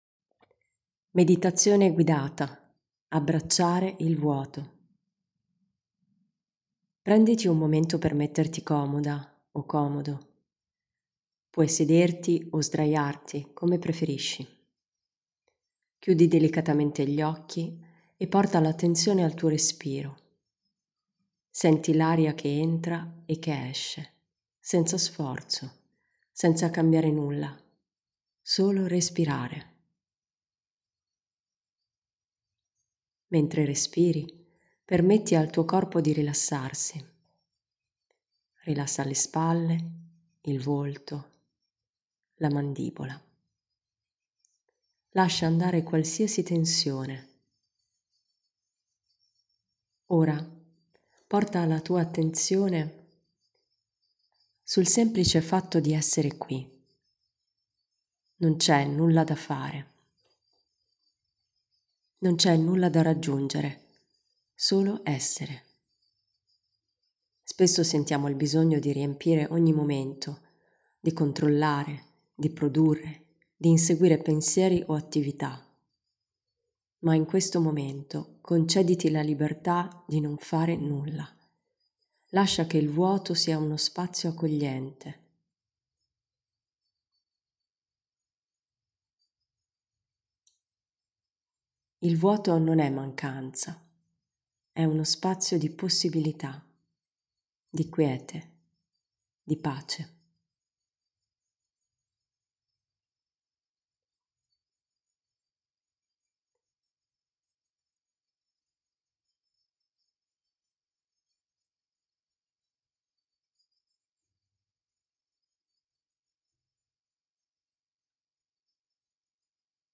Meditazione guidata sul vuoto - Casa Yoga Milano
Ho creato una meditazione guidata di 5 minuti, per accompagnarti in questi momenti di vuoto.